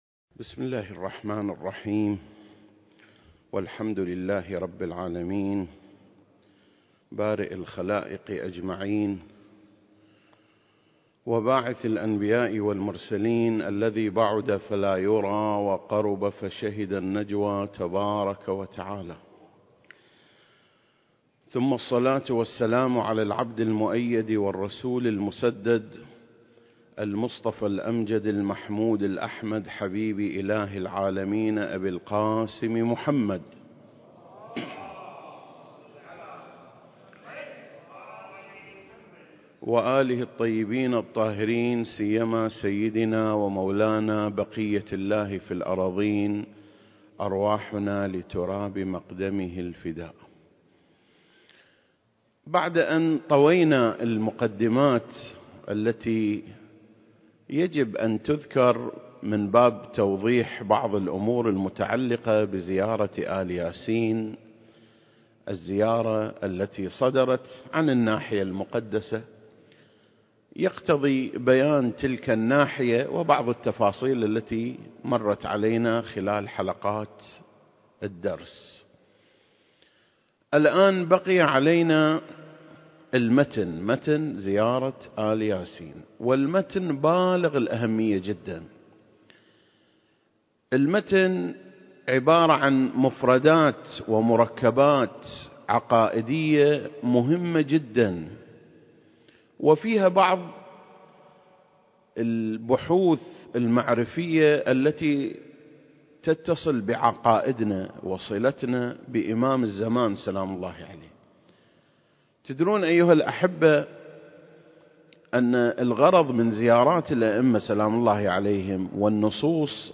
سلسلة: شرح زيارة آل ياسين (18) المكان: مسجد مقامس - الكويت التاريخ: 2021